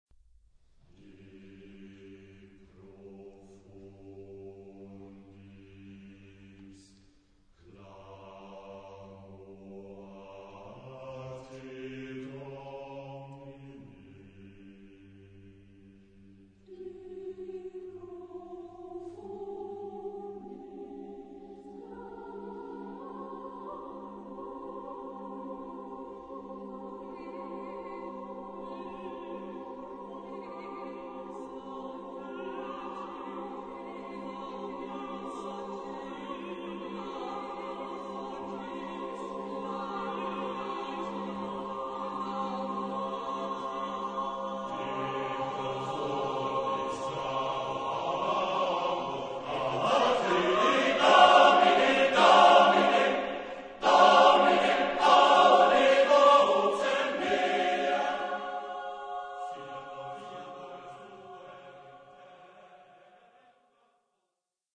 SSSAAATB (8 voices mixed) ; Full score.
Motet. Sacred.